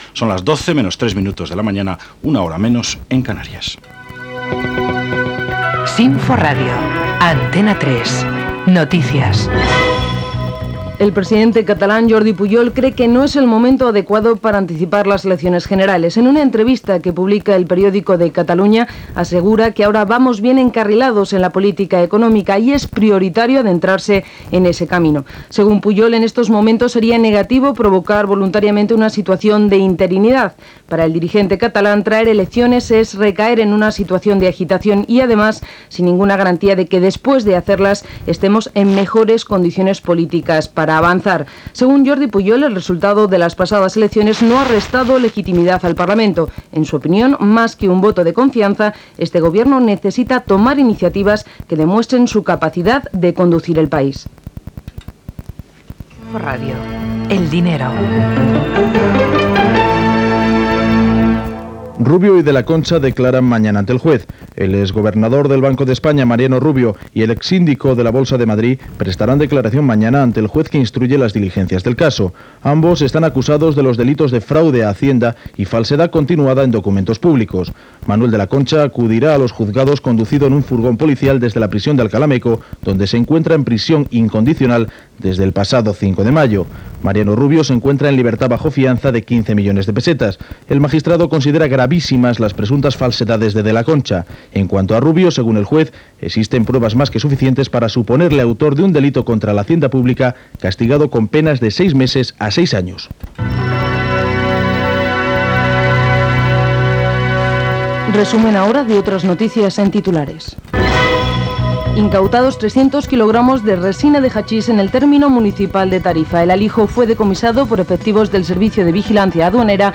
Notícies: Jordi Pujol considera que no convé avançar les eleccions generals, declaració de Mariano Rubio, etc. Publicitat i música.
Informatiu
FM